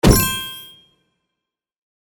Shield Item RPG 2.mp3